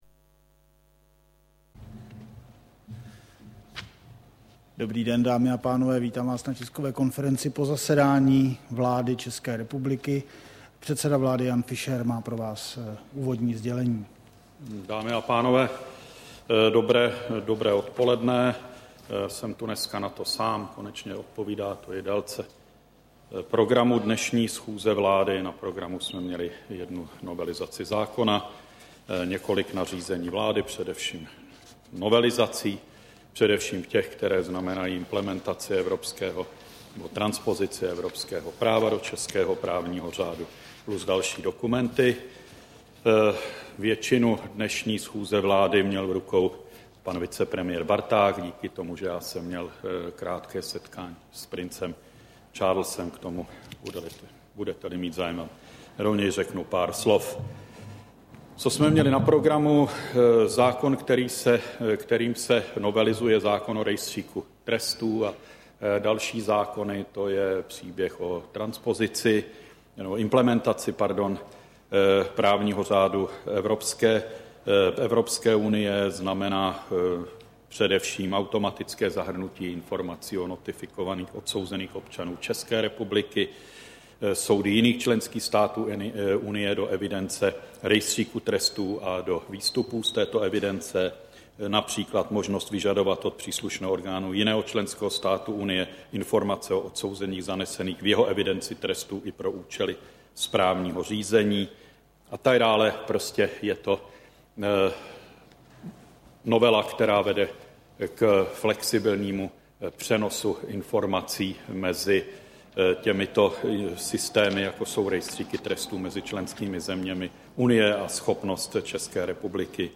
Tisková konference po jednání vlády, 22. března 2010